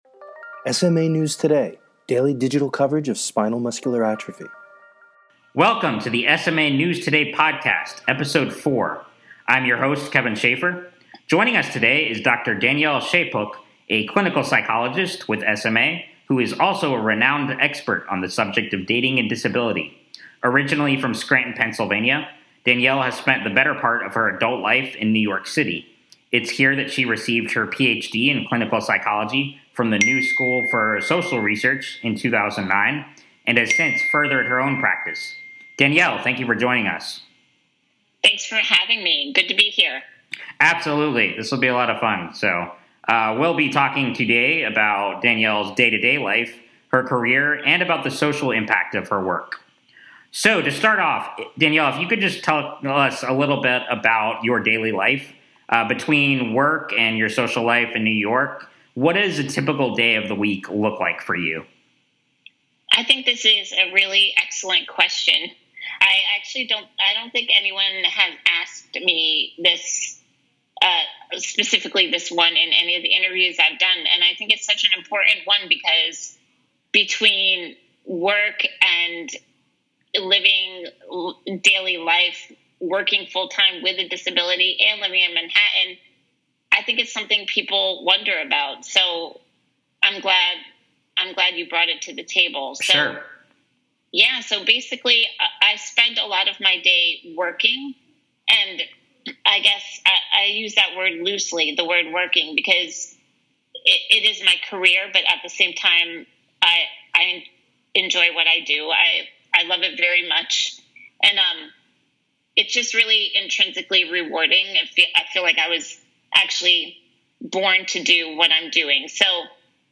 SMA News Today Podcast 4 - Interview